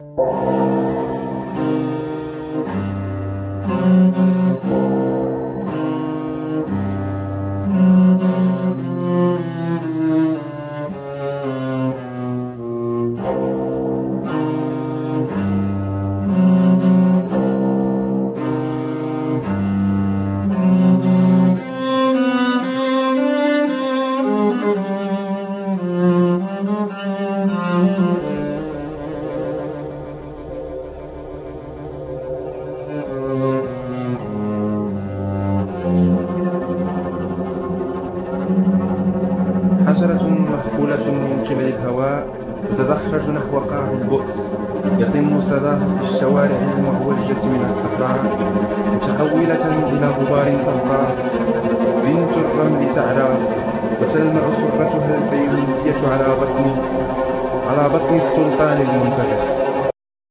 Guitar,Highland pipes,Bodhran
Cello
Djemba,Cajon peruano,Caja
Vocals
Recitado
Pandereta